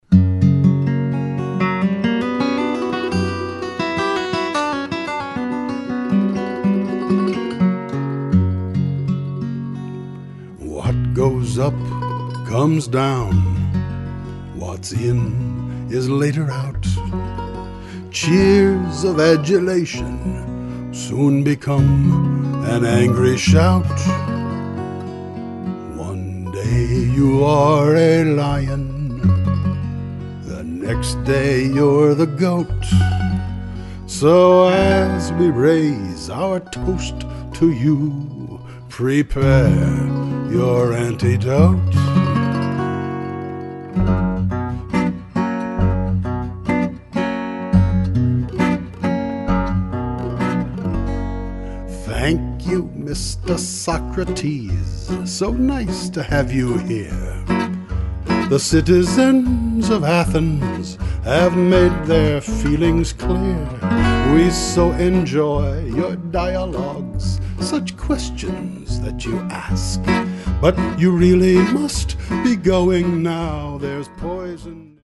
came by to play upright bass on most of the tracks.